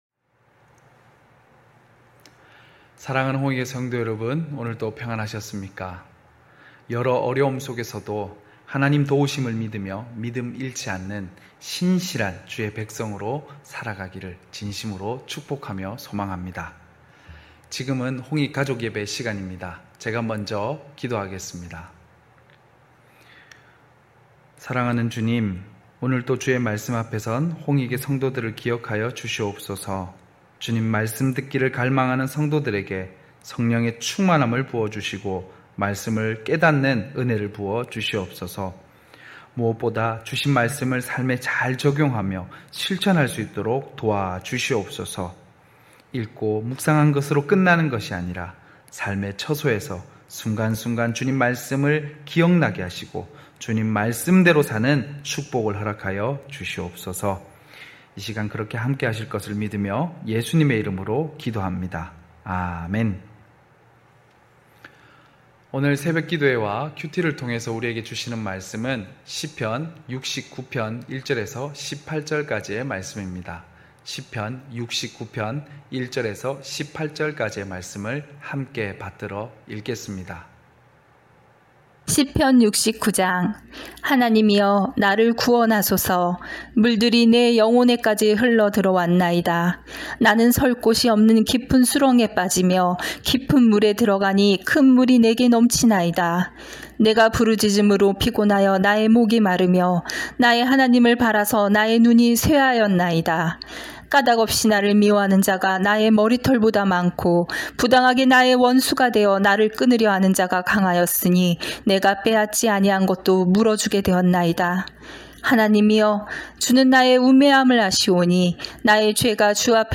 9시홍익가족예배(8월26일).mp3